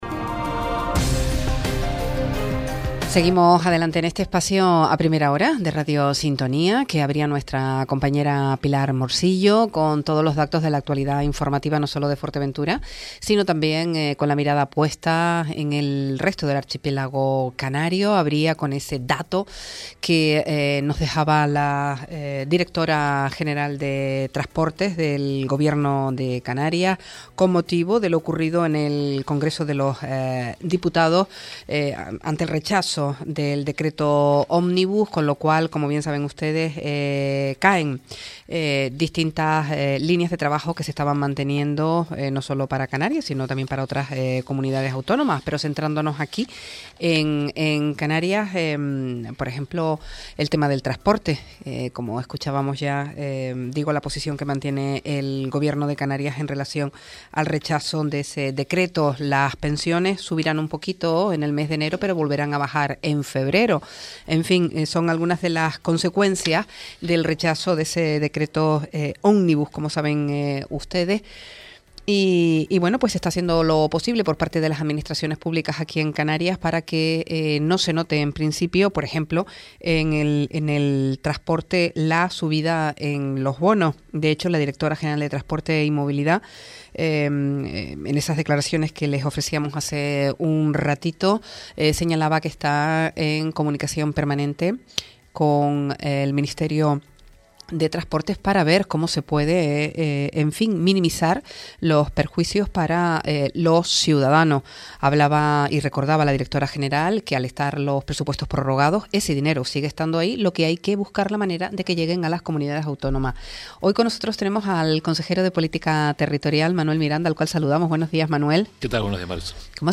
Entrevistas A Primera Hora